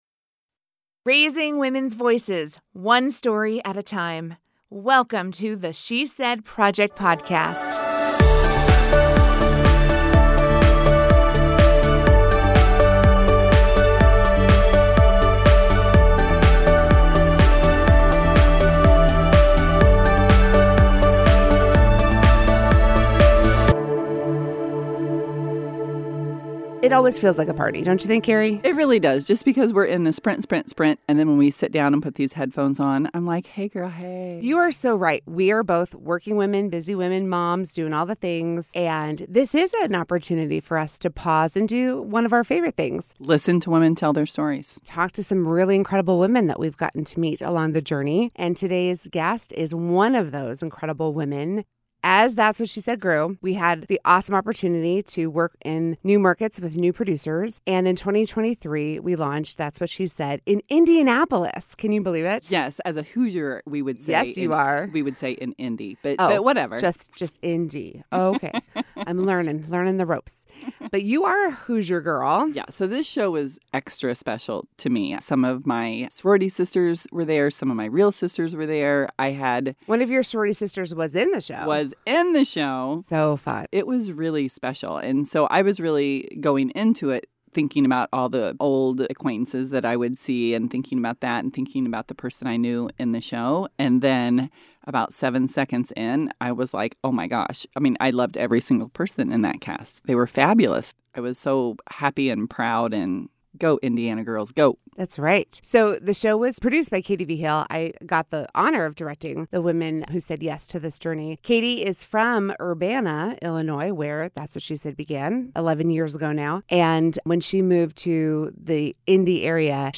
The She Said Project Podcast is recorded in partnership with Illinois Public Media.